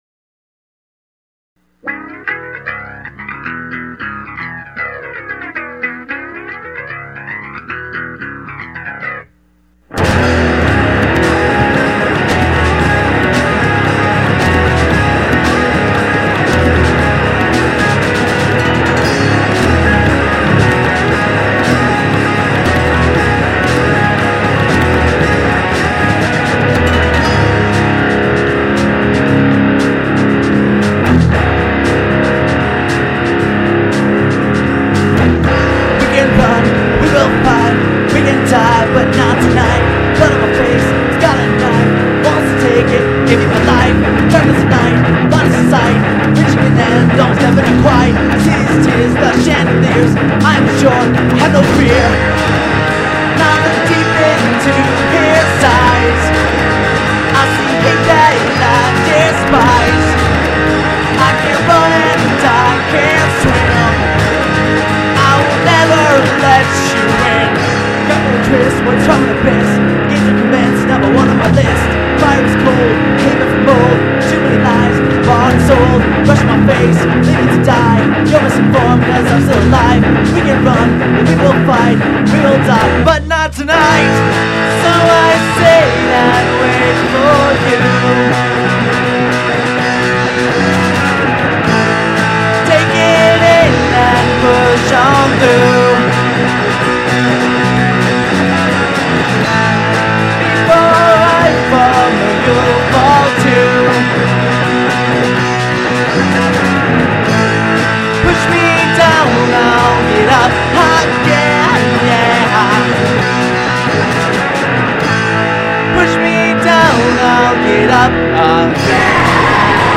SORRY FOR POOR MP3S, OUR EP DROPS JANUARY 2004